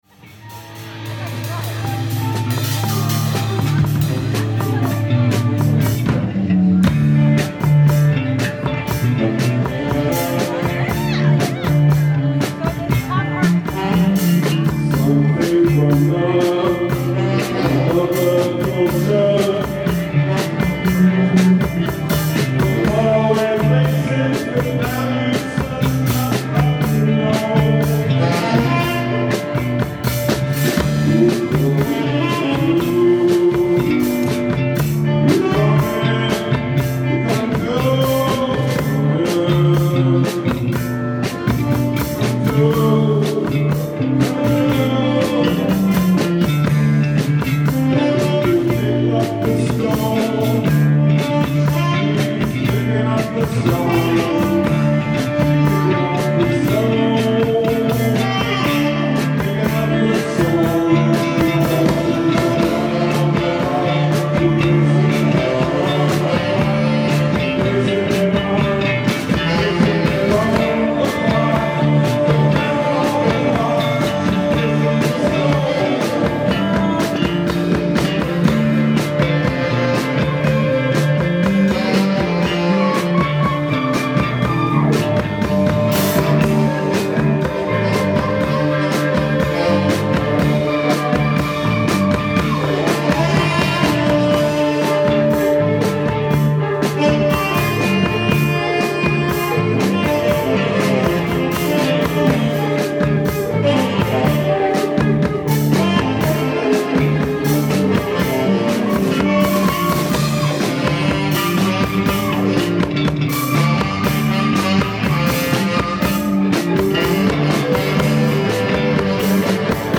ALL MUSIC IS IMPROVISED ON SITE